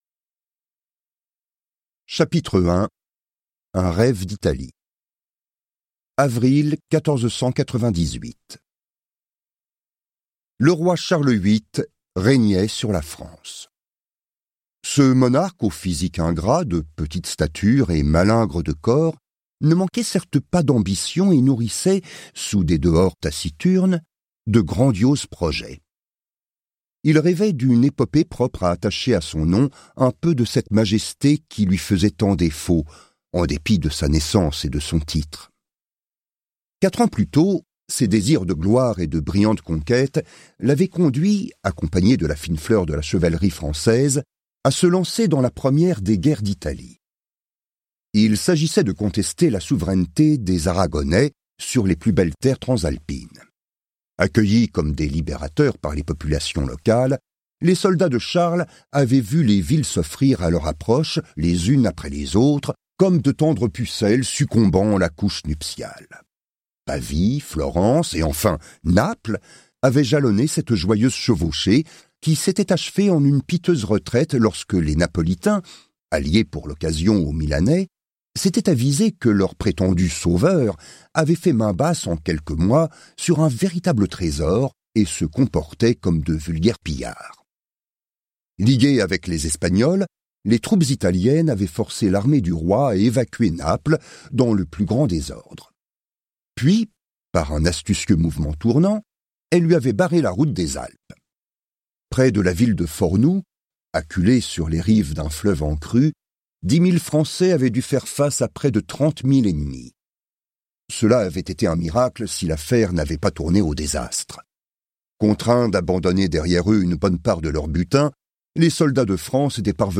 je découvre un extrait - Bayard et le crime d'Amboise de Eric Fouassier